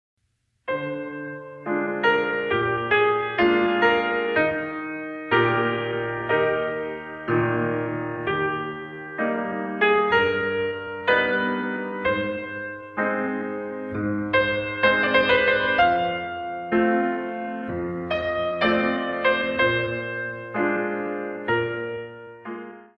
Pianist
In 2